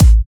Kick 17 (First Of The Year).wav